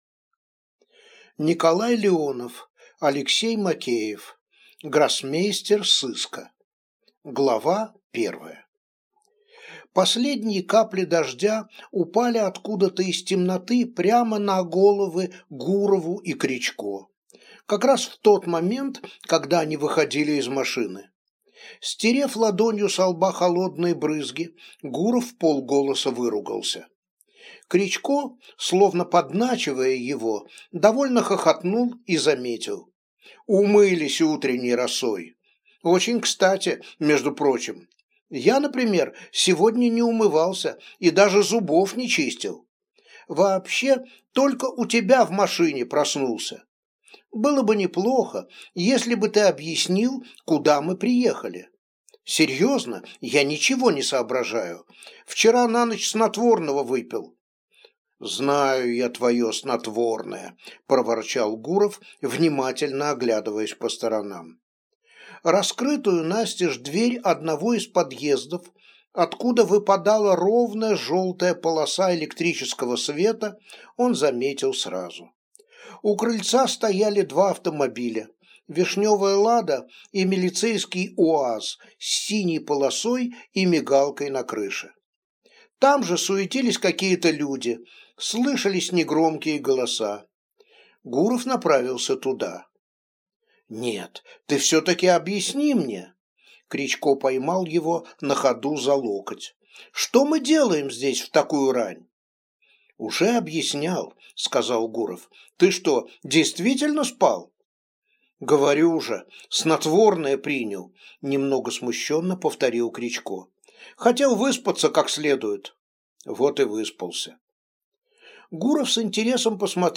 Аудиокнига Гроссмейстер сыска | Библиотека аудиокниг